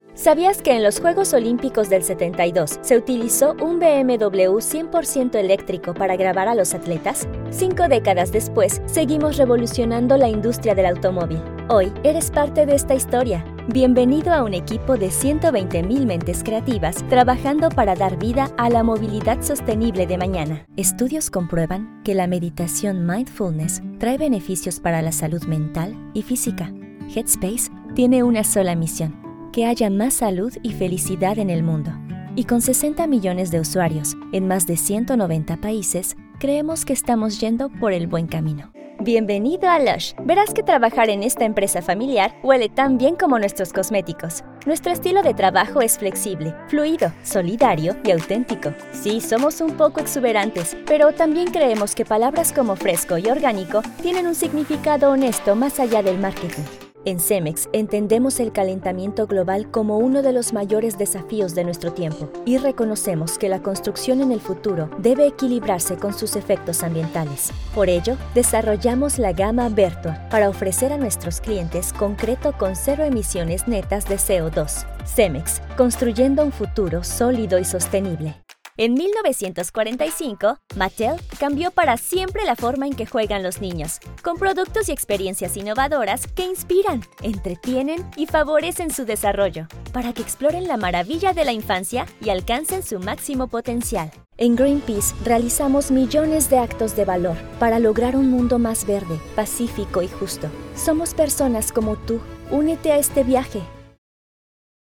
Espagnol (Amérique Latine)
Jeune, Naturelle, Amicale, Douce, Corporative
Corporate